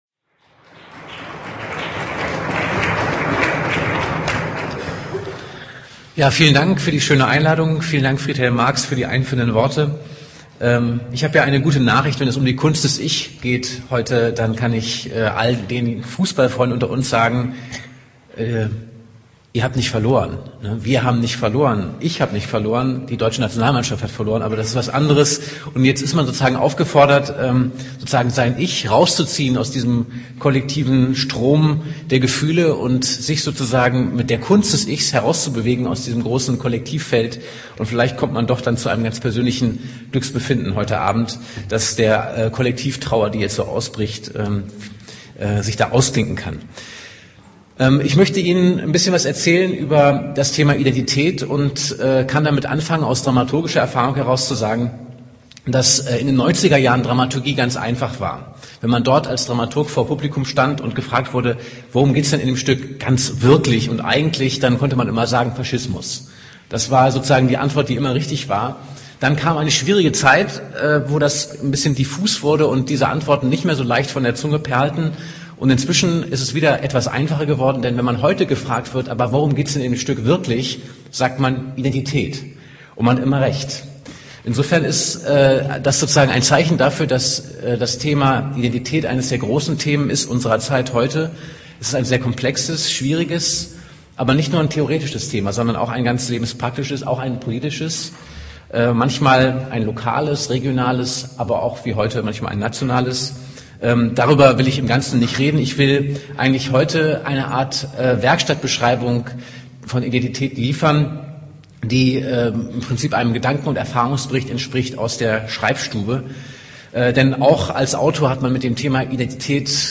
Termine und Themen der Poetikprofessur 2008